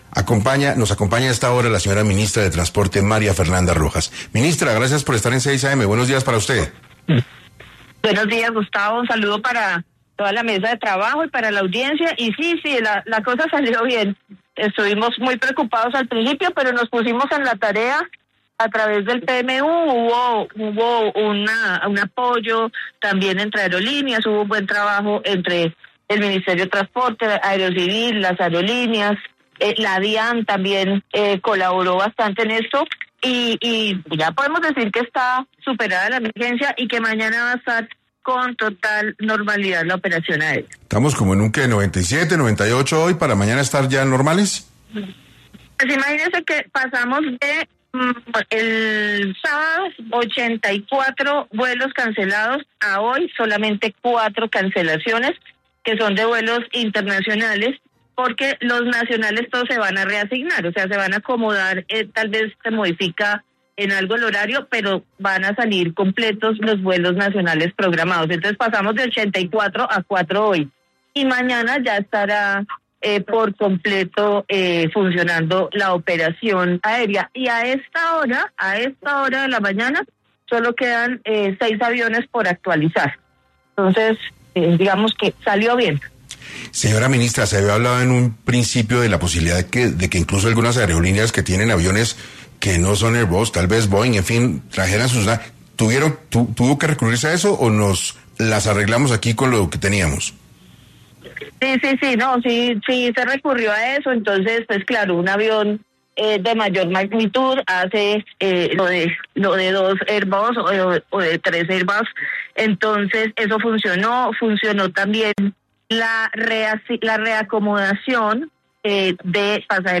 En una entrevista a 6AM de Caracol Radio, la ministra detalló los esfuerzos coordinados que permitieron resolver la situación, que inicialmente generó gran preocupación.